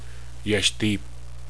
English: Yashdeep, Yashdip, Yash + Deep
Pronunciation: